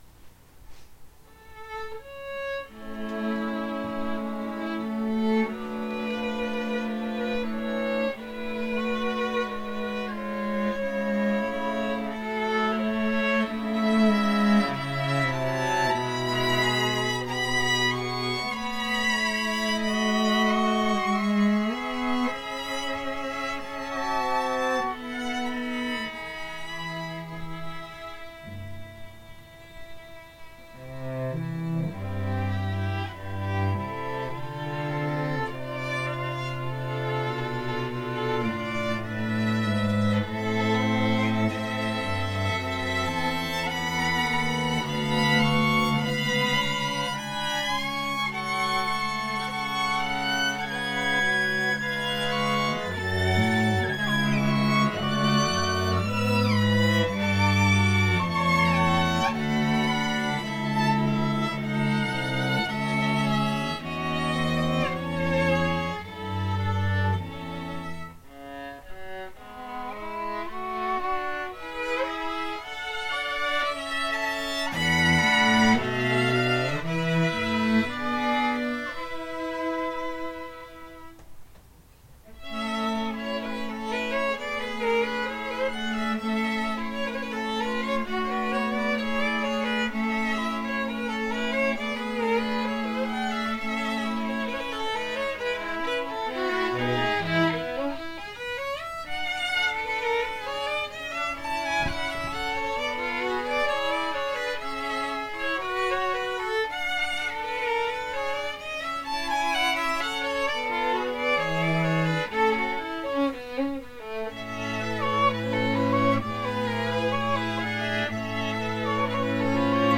Chamber Groups
Moderato - allegro